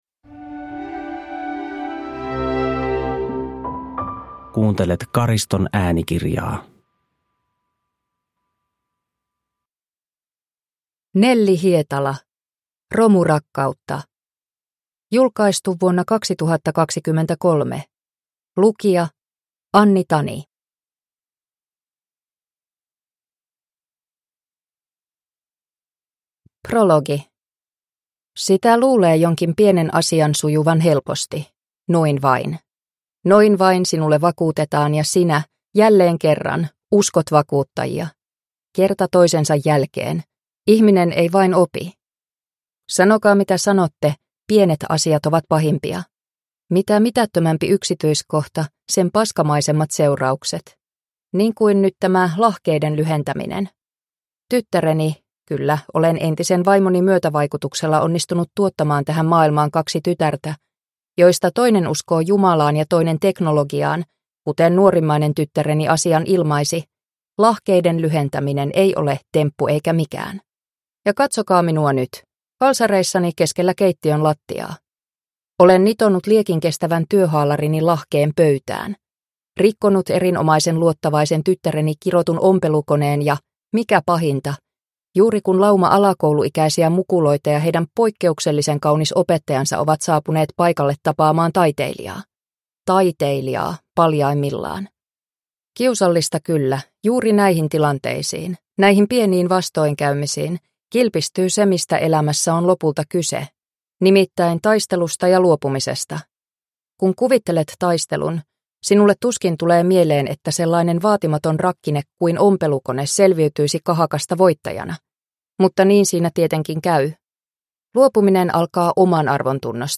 Romurakkautta – Ljudbok – Laddas ner